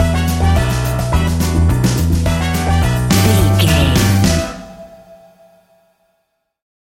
Ionian/Major
flamenco
maracas
percussion spanish guitar